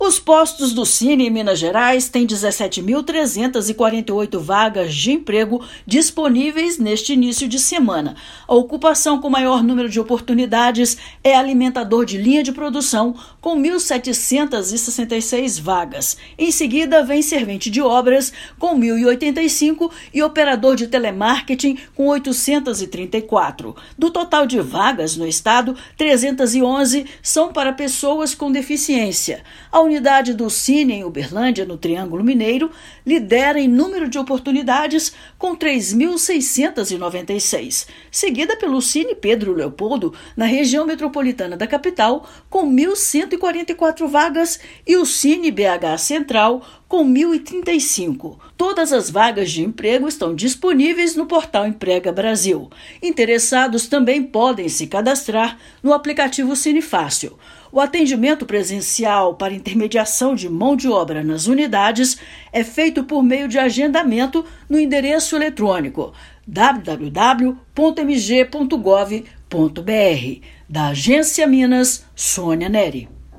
Pela internet, interessados em ingressar no mercado de trabalho podem pesquisar oportunidades em todo o estado. Ouça matéria de rádio.